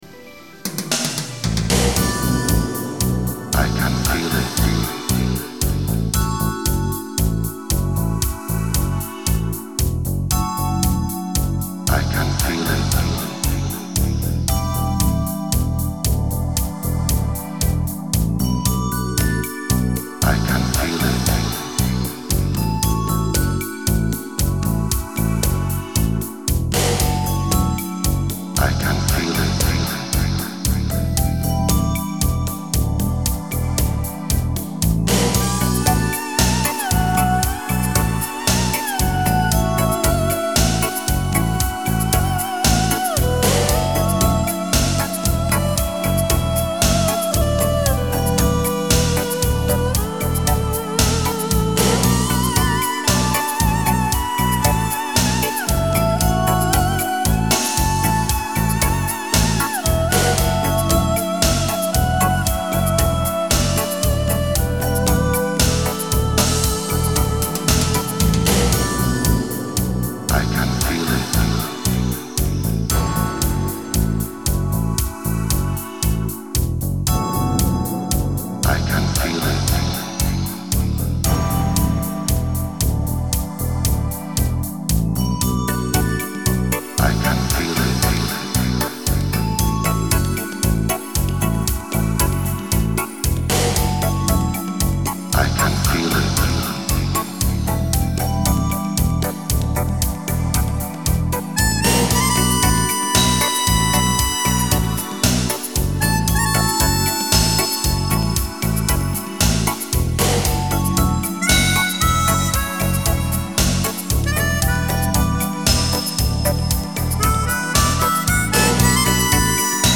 Нью эйдж New age